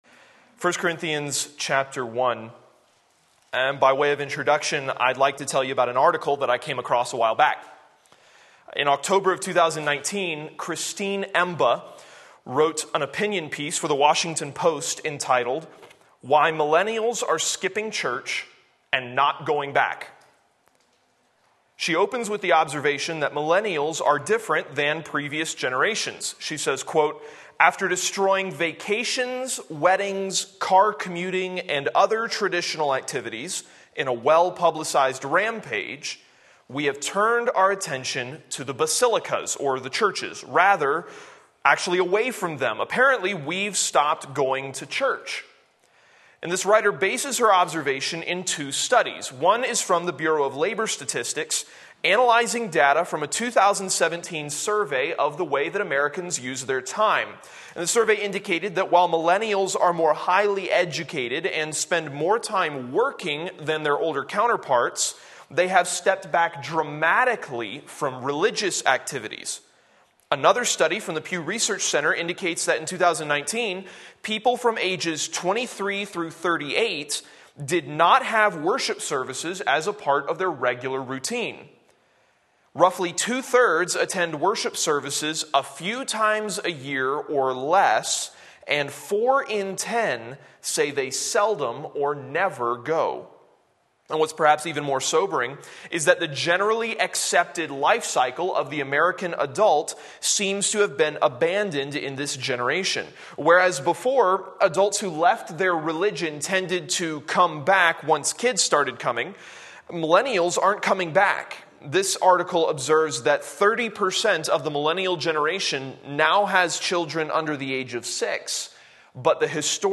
Sermon Link
What Effective Ministry Looks Like 1 Corinthians 1:18-25 Sunday Morning Service